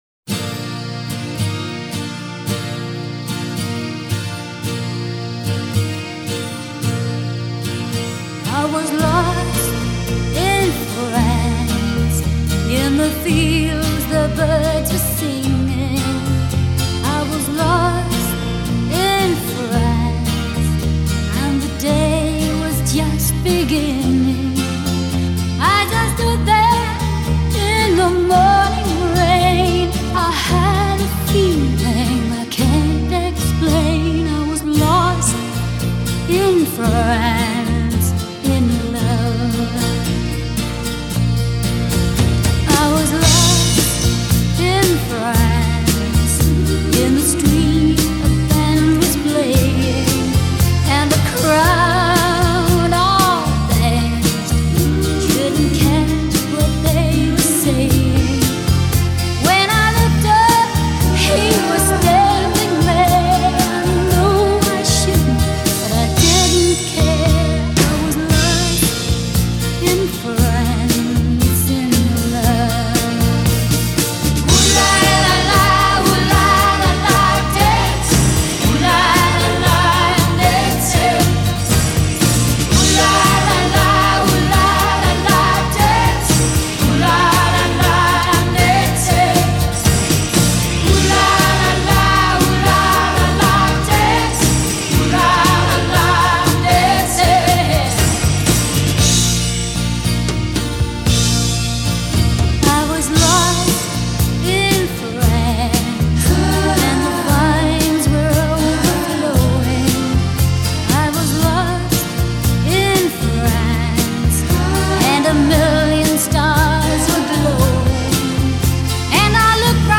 после чего её голос приобрел лёгкую хрипотцу.